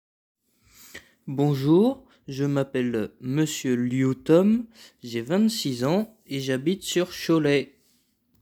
Voix normale